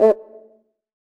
HCUICA LW.wav